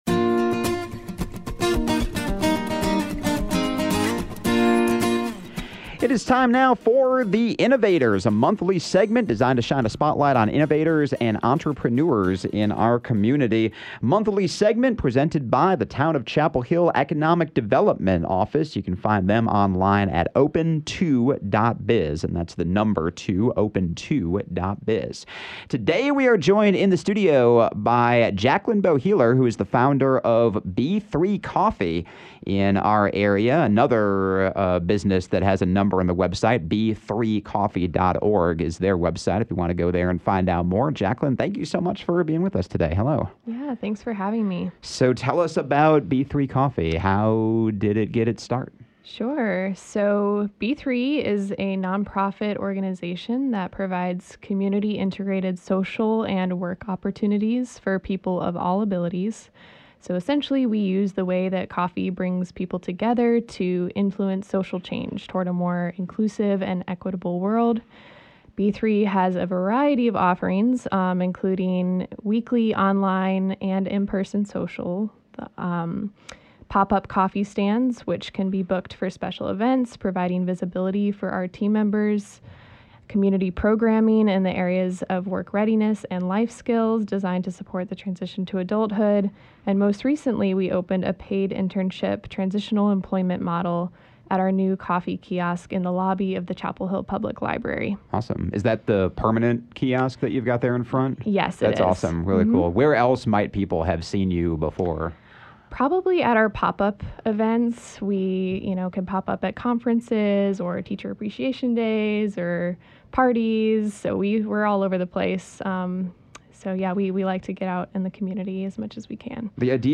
“The Innovators” is a monthly segment, presented by Town of Chapel Hill Economic Development, designed to shine a spotlight on innovators and entrepreneurs in our community.